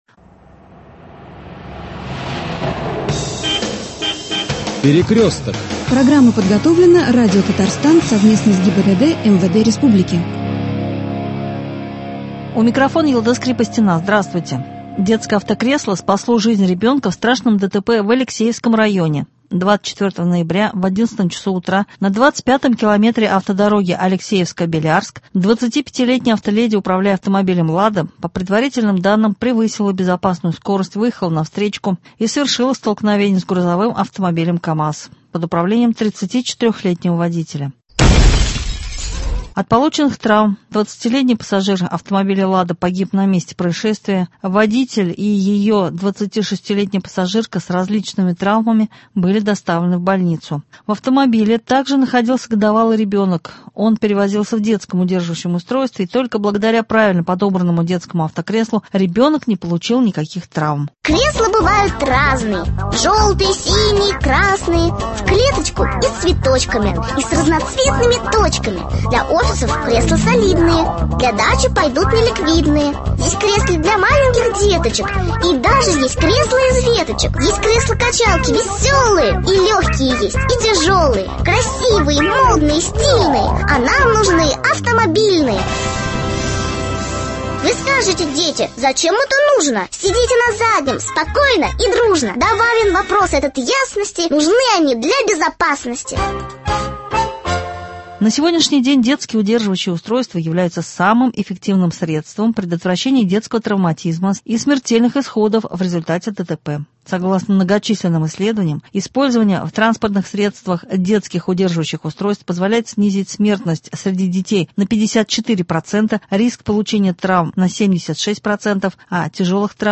О ситуации в Казани рассказывает Игорь КУЛЯЖЕВ — Заместитель руководителя Исполнительного комитета г.Казани – председатель Комитета внешнего благоустройства г. Казань